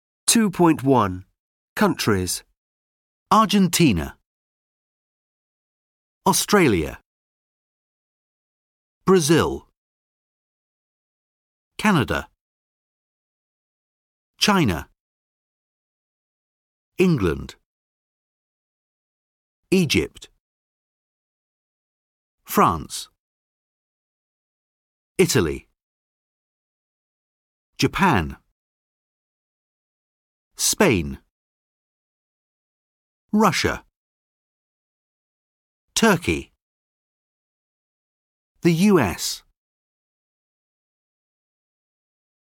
Argentina (ɑːʤənˈtiːnə)
Australia (ɒˈstreɪliːə)
Brazil (brəˈzɪl)
The United States ( ðə jʊˈnaɪtɪd steɪts )